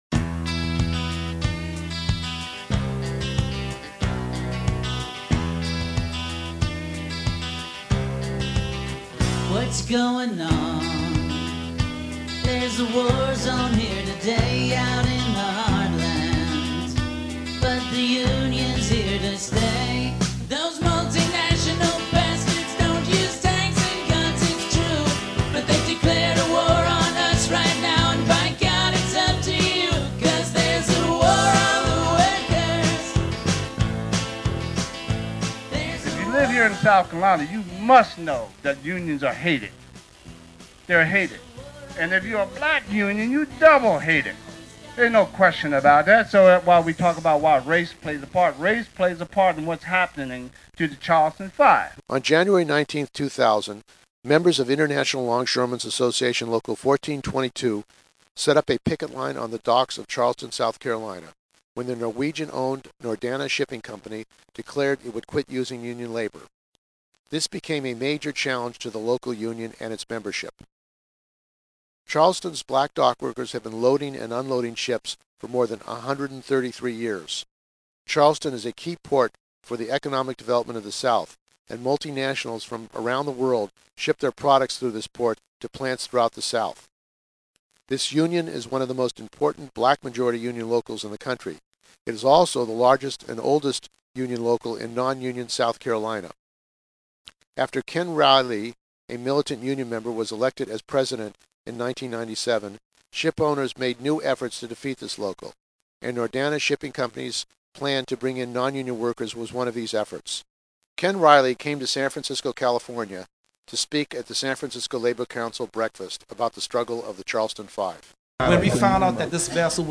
This is a audio report on the struggle of the Charleston Five longshore workers who were charged with riot and conspiracy to riot for picketing a non-union ship the Nordana in January 2000 on the Charleston docks.